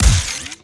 GUNTech_Sci Fi Shotgun Fire_02_SFRMS_SCIWPNS.wav